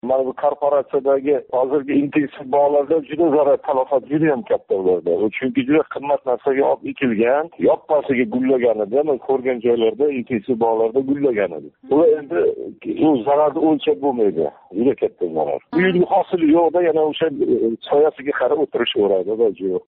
Самарқандлик фермер фикрлари